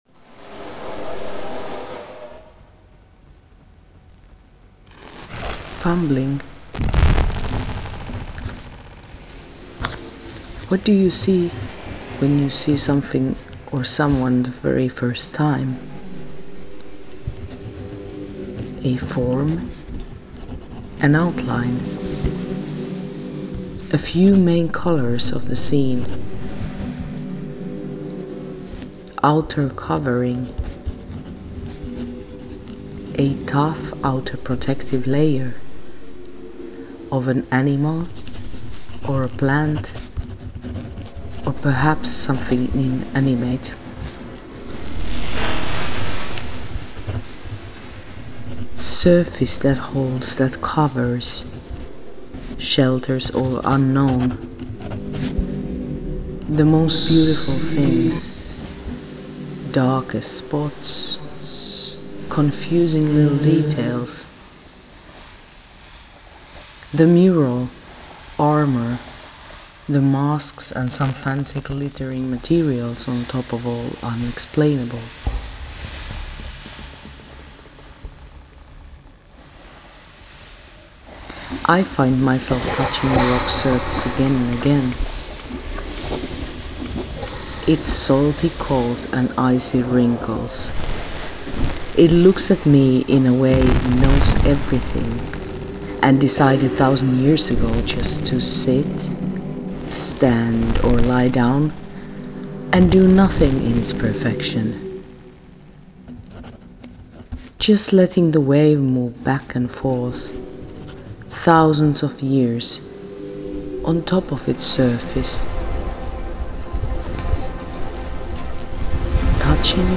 For a month she continued walking the same shoreline, the middle of Vadsø town, in northern Norway.
We went through computational and intuitive approaches to interpreting the collected images and sound, applying digital processes that analytically extract what matters in an image as well as analogue, tactile and materic transformations of images, understood and reinterpreted through the act of drawing.
surfacetension_sounscape_v3.mp3